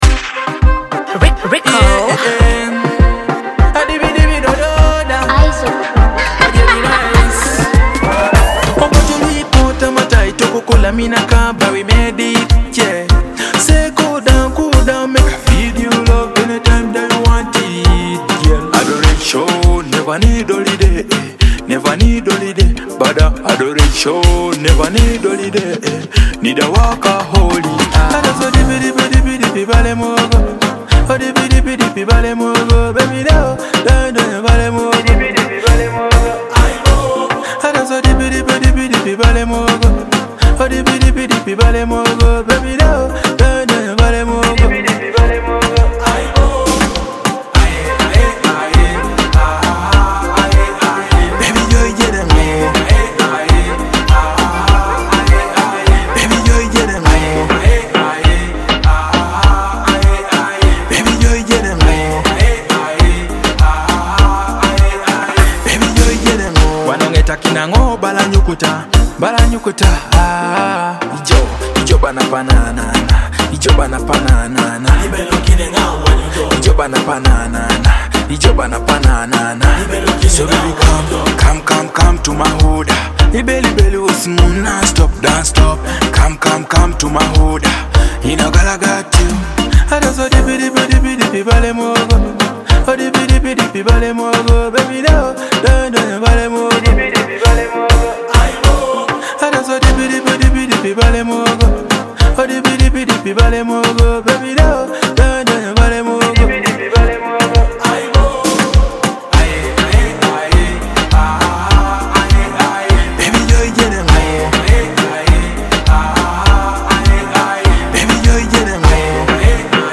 blending modern Dancehall rhythms with Teso musical elements
With its lively production and magnetic energy